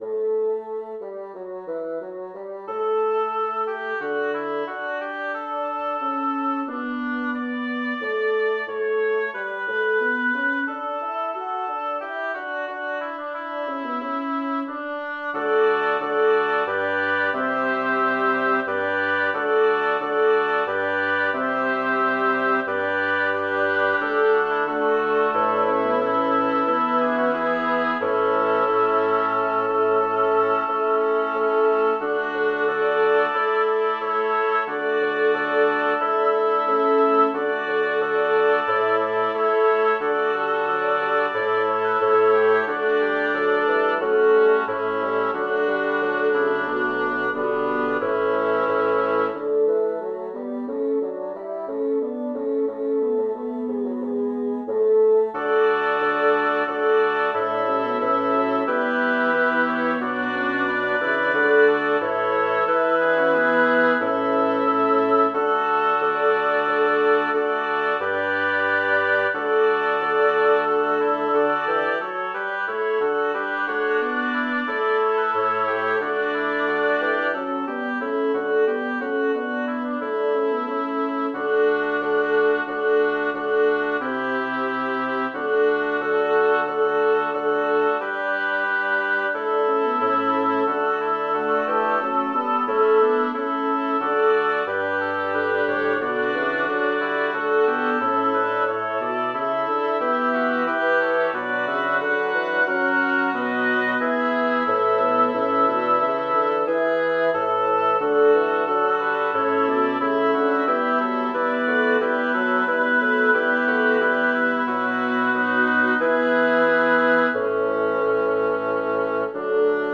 Title: Primas quidam venit ad iesum Composer: Melchior Vulpius Lyricist: Matthew 9, 18 & 23 - 24create page Number of voices: 6vv Voicing: SAATBB Genre: Sacred, Motet
Language: Latin Instruments: A cappella